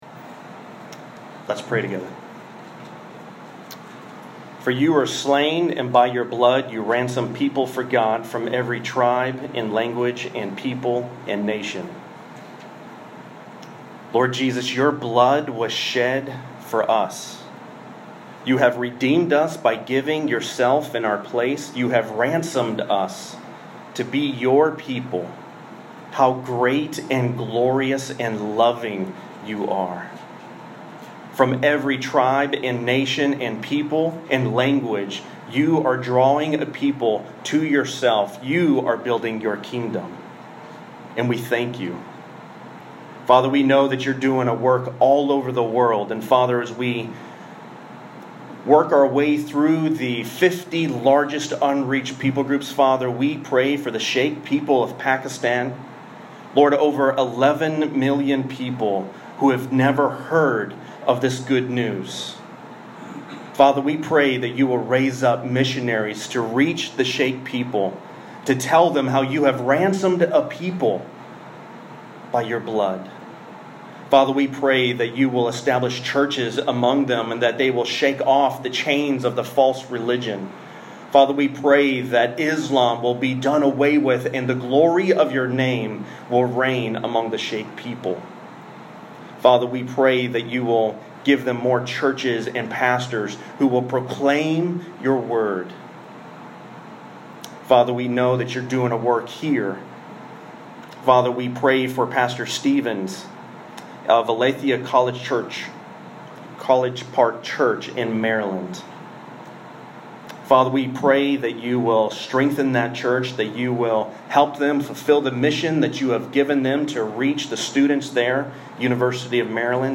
1 Corinthians 8:1-6 (partial recording) - Redeemer Church KG